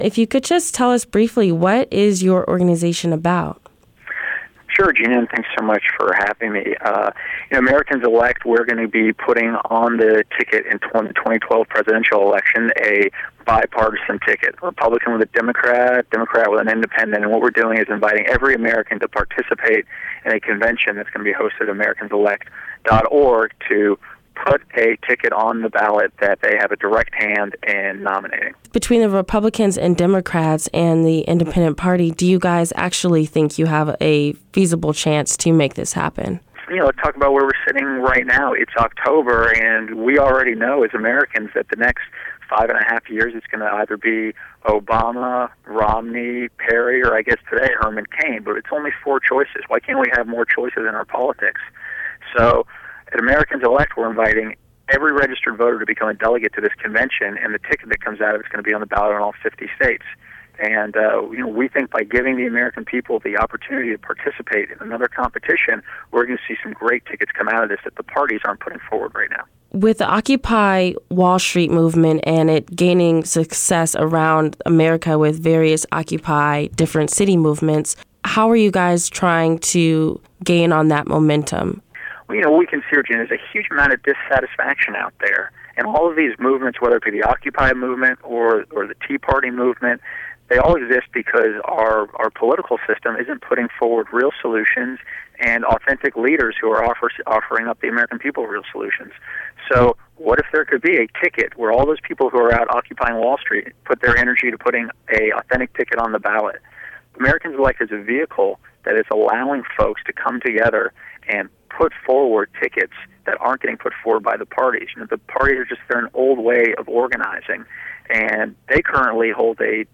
The non-profit organization called Americans Elect wants to nominate a presidential ticket tat answers directly to voters. In this interview